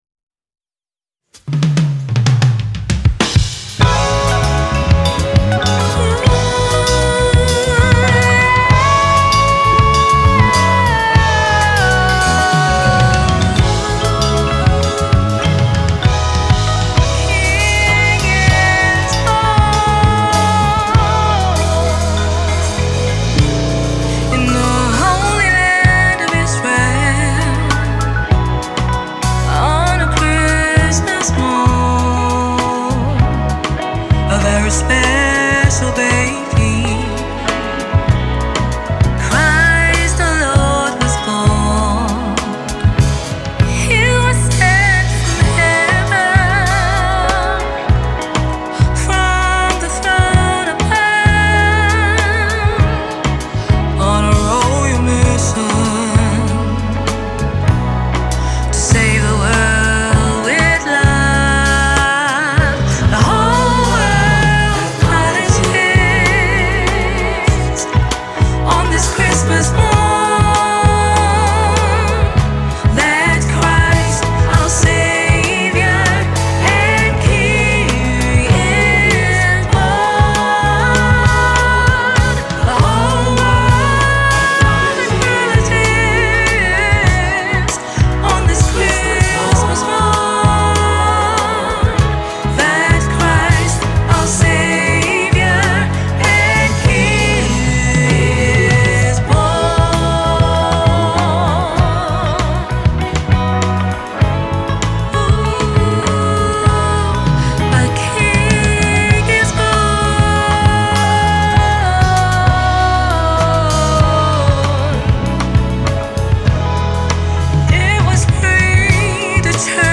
Genres: Christian & Gospel, RnB-Jazz
BPM: 98